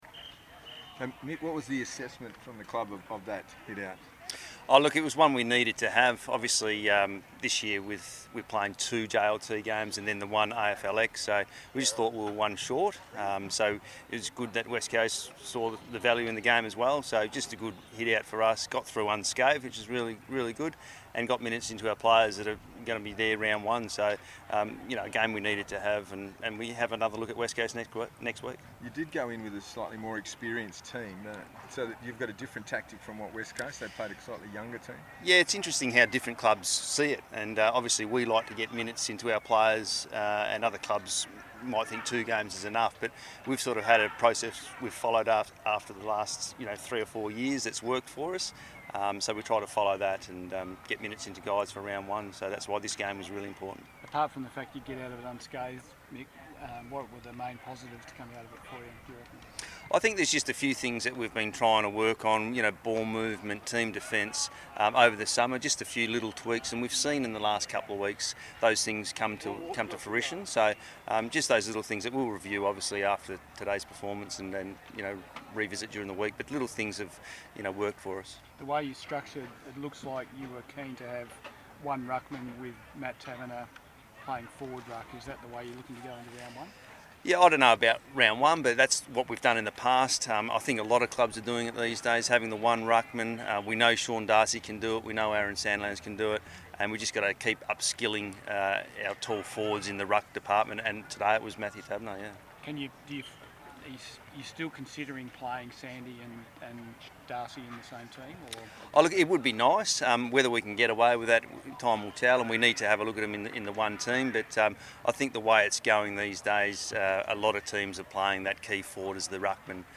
media conference